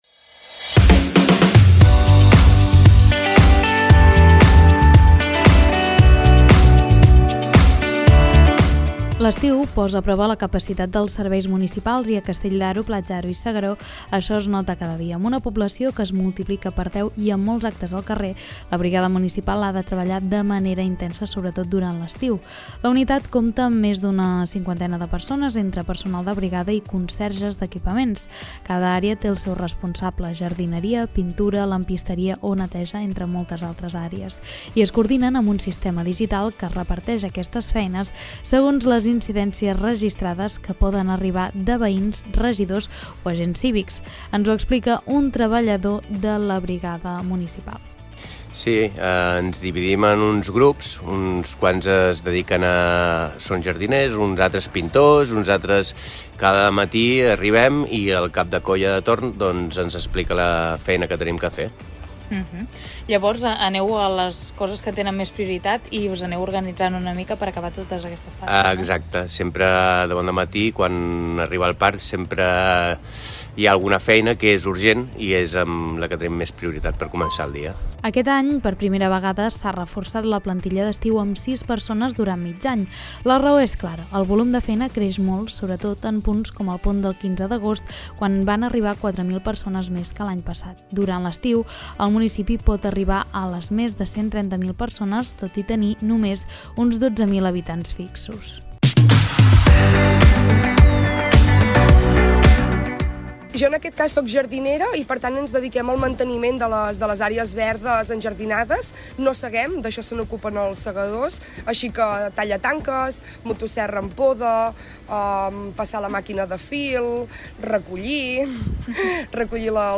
Reportatge-brigada-PDA-BO.mp3